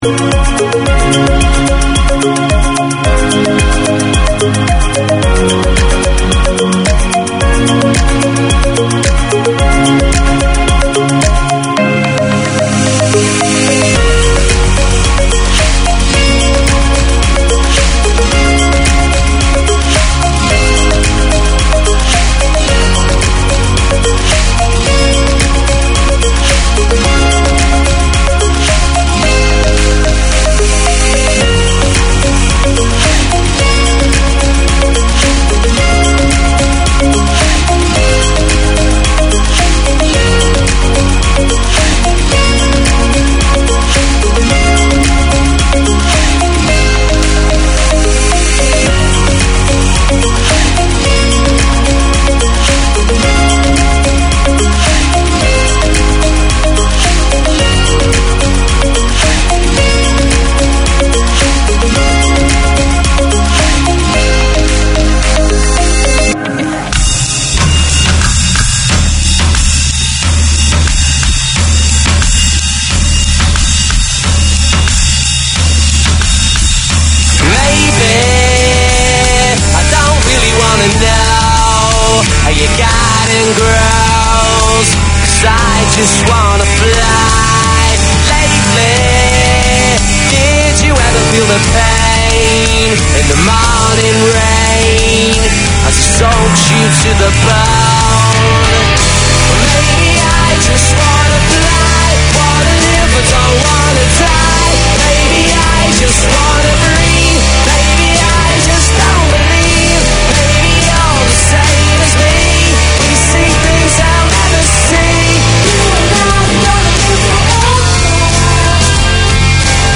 It is both a reflection of the Khmer community in Auckland and an avenue for new Cambodian migrants. Music mixes with news local and global, interviews, religious topics, settlement topics and issues, with talkback.